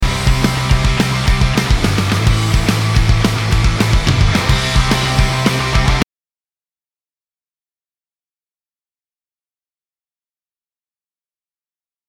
немного панка
ваш вариант ощутимо громче
тощее все стало точнее все зателефонило крепко выравниваем громкость ...и